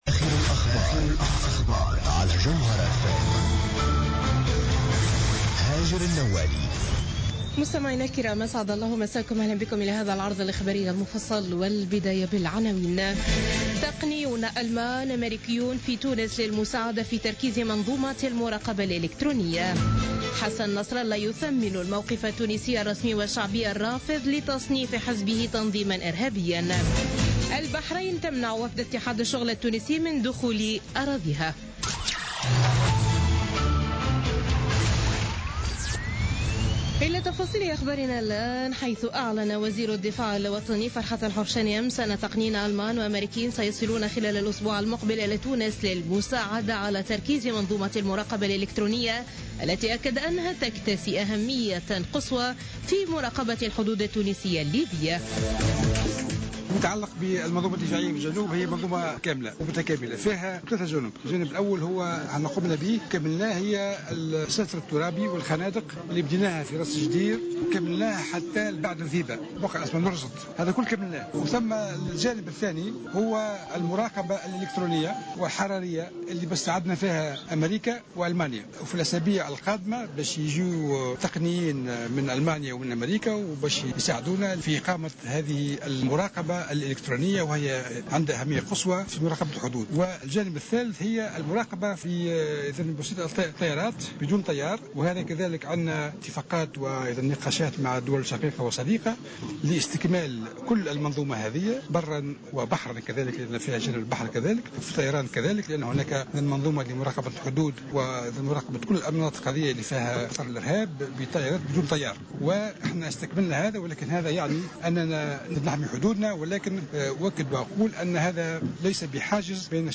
نشرة أخبار منتصف الليل ليوم الاثنين 7 مارس 2016